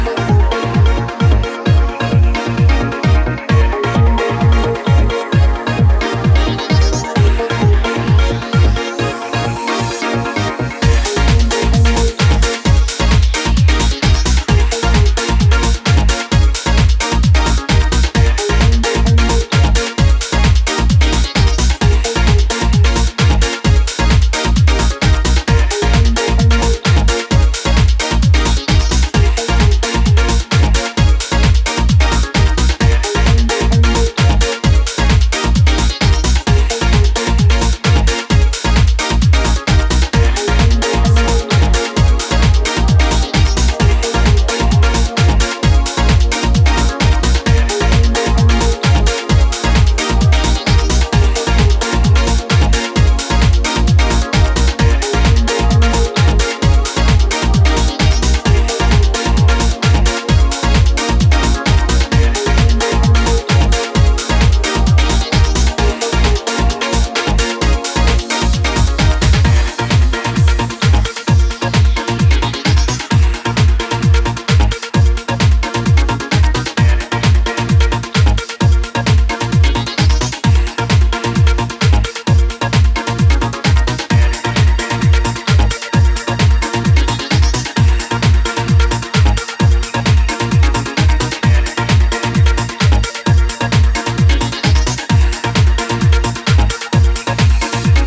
plenty of raw energy